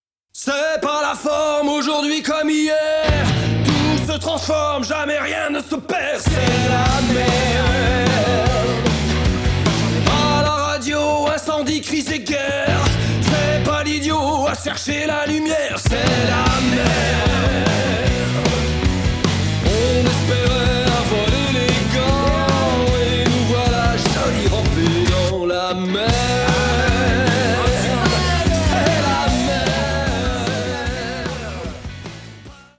« Un cri rock lucide et ironique face au chaos du monde,
à chanter à pleins poumons. »
Basse
Batterie
Guitares